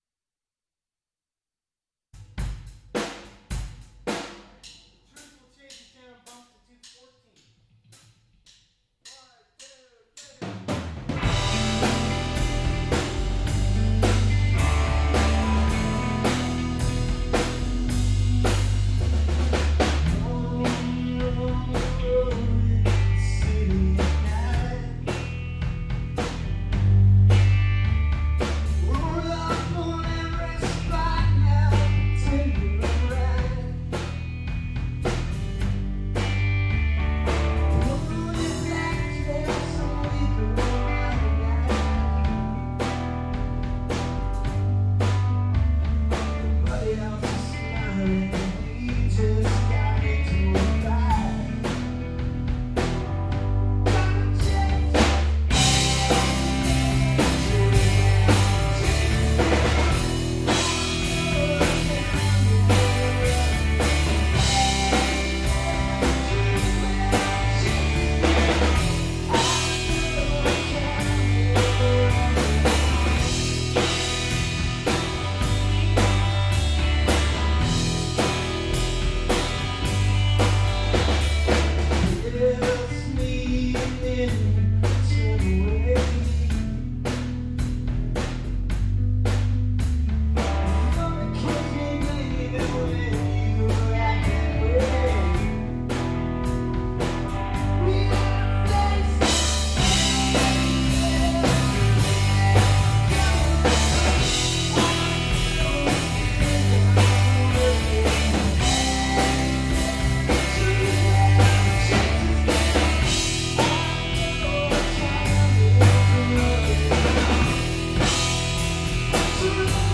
The Truth - Fast Tempo Shorter Arrangement